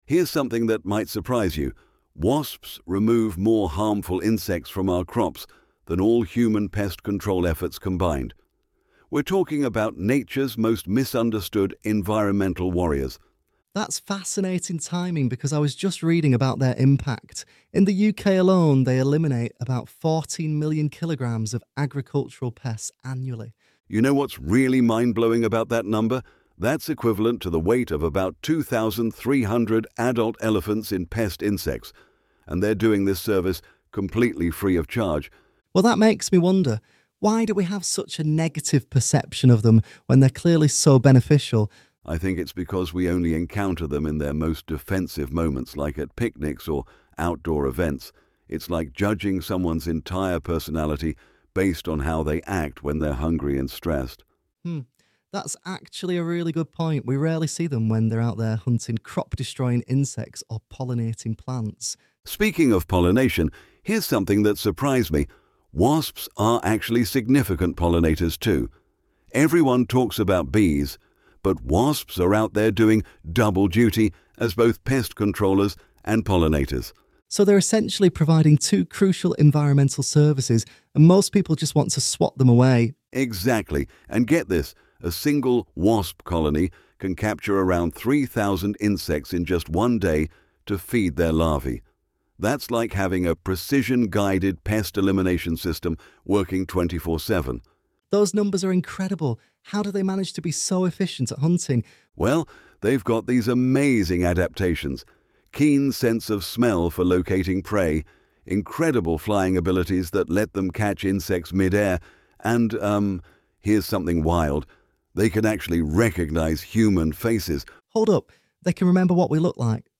ElevenLabs_Wasp_Warriors_Natures_Unsung_Pest_Controllers-1.mp3